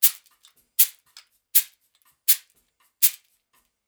Index of /90_sSampleCDs/USB Soundscan vol.36 - Percussion Loops [AKAI] 1CD/Partition A/02-60SHAKERS
60 SHAK 05.wav